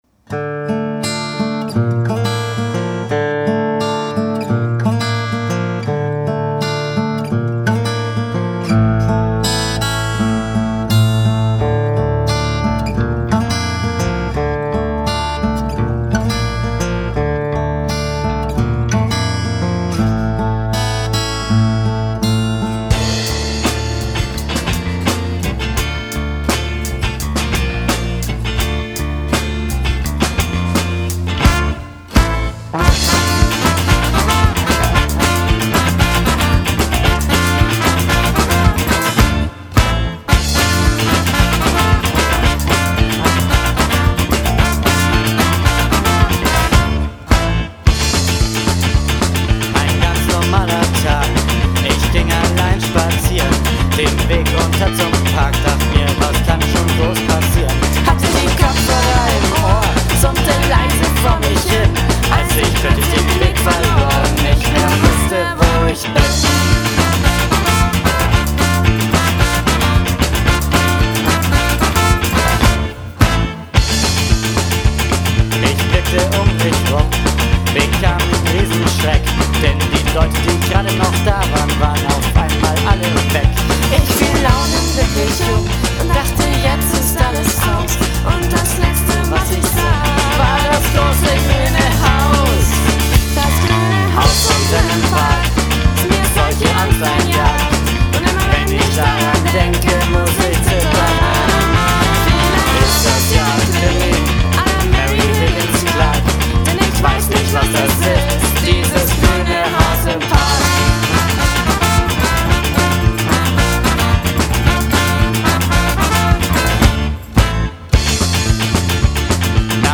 Trompete
Saxophon
Bass
Gesang
Schlagzeug
Posaune
Orgel
Gitarre, Gesang